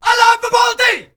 All Punjabi Vocal Pack